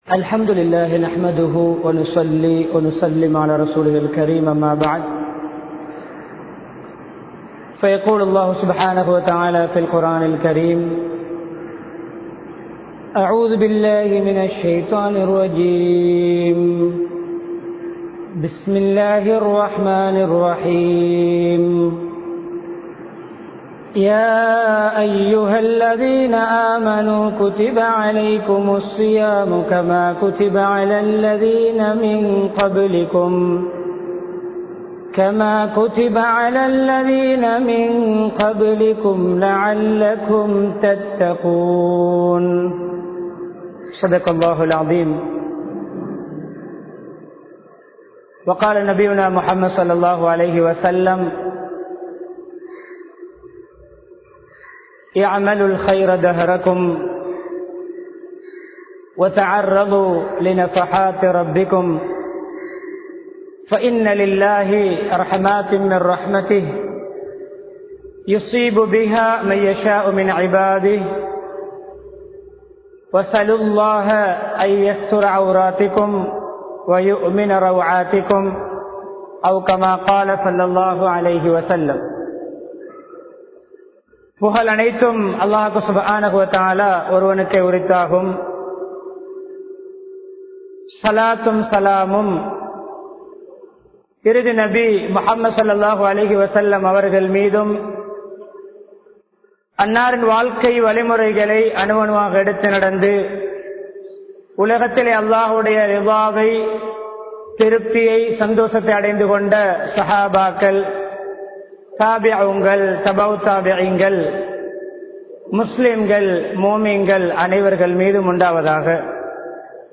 ரமழானை சிறப்பாகக் கழிப்போம் | Audio Bayans | All Ceylon Muslim Youth Community | Addalaichenai
Mannar, Uppukkulam, Al Azhar Jumua Masjidh